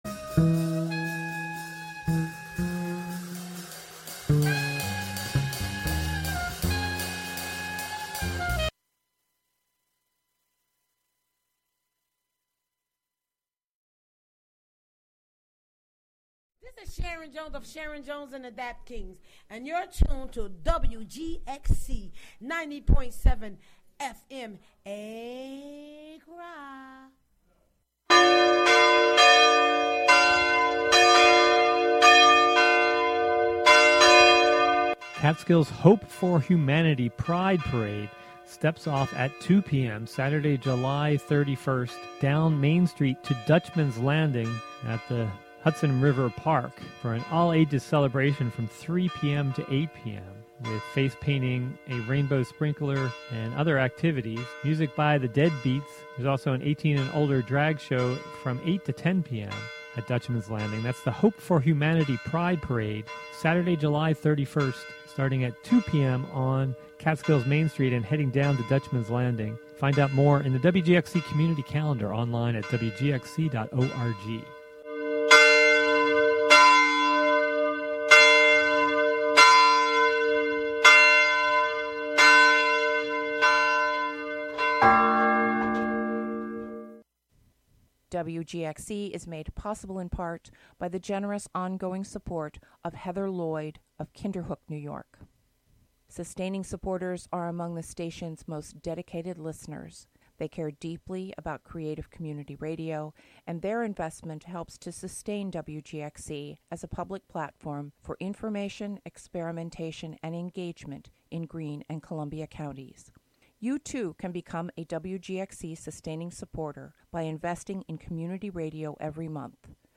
"Wave Farmacy" is a talk show where callers bring emotional/relationship/life problems to be discussed from an engineering perspective by engineers.